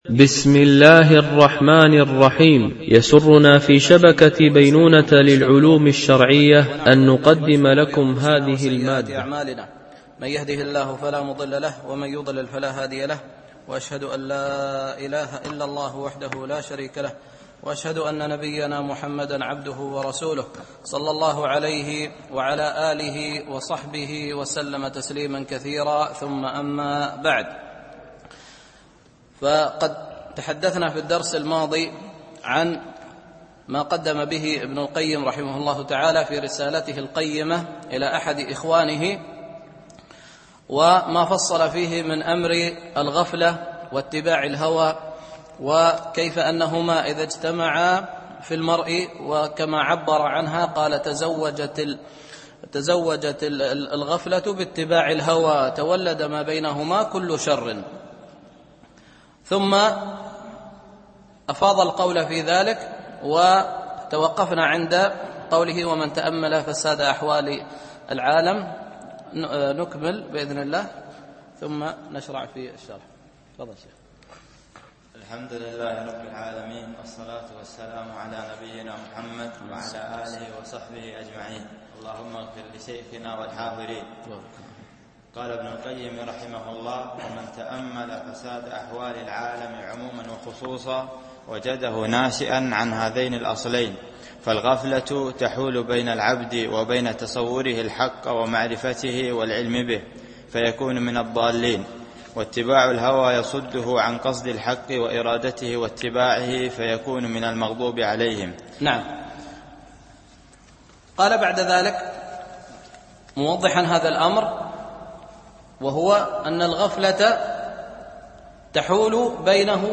شرح رسالة ابن القيم إلى أحد إخوانه ـ الدرس الثاني